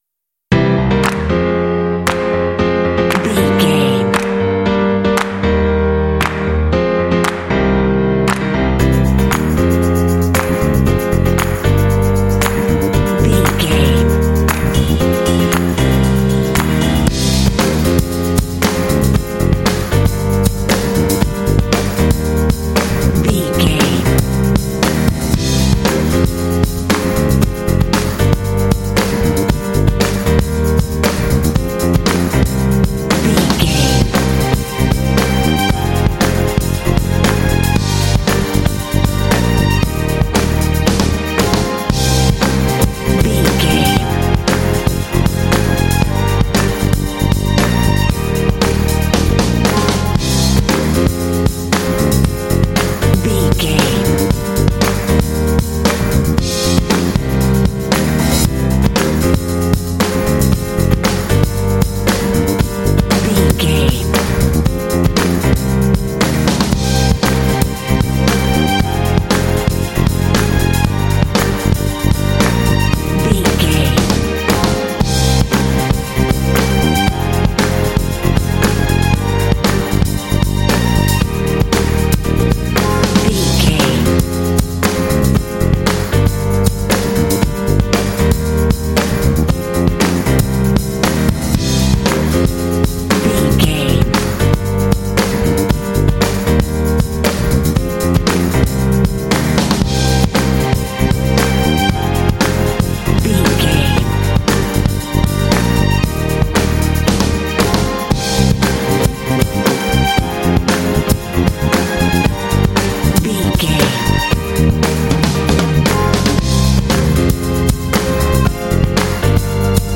Aeolian/Minor
funky
happy
bouncy
groovy
piano
percussion
drums
bass guitar
strings
Funk